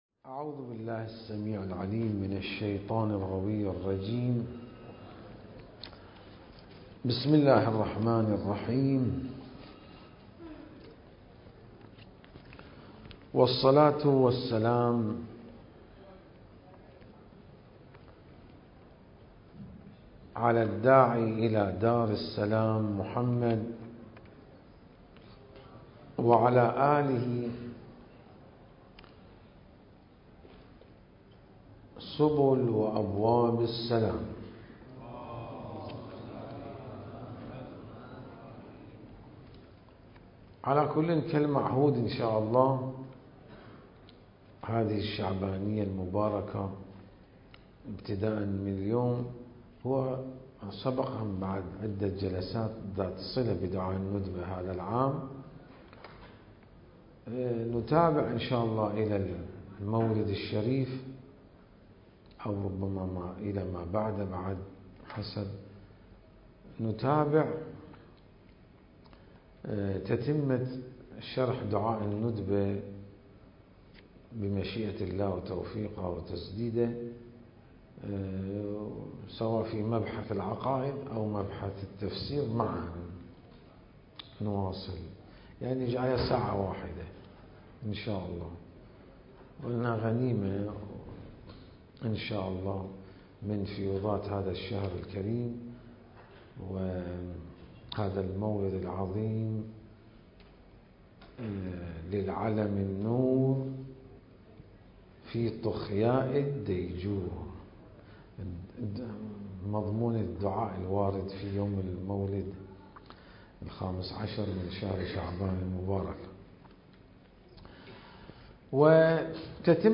المكان: العتبة العلوية المقدسة